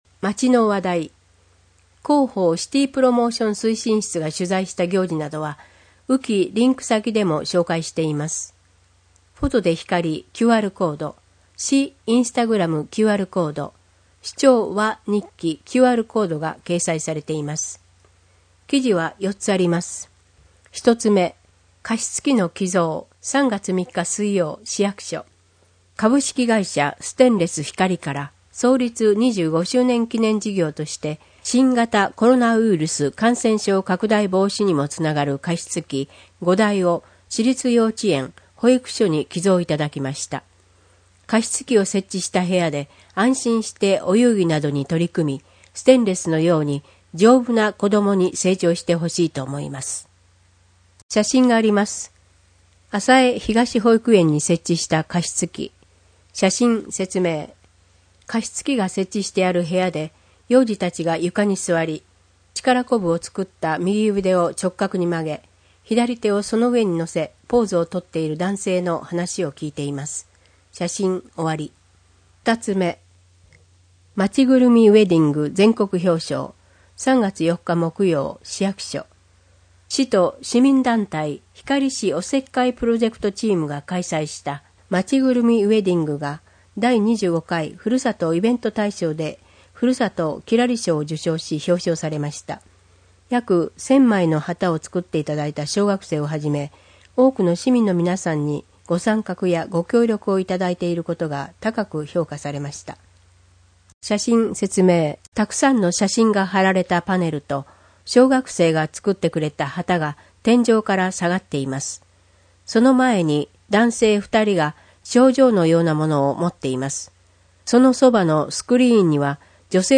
こえの広報 について
広報ひかりでは、 ボランティアグループ「こだまの会」の協力により文字を読むことが困難な視覚障害者や高齢者のために広報紙の内容を音声でもお届けしています。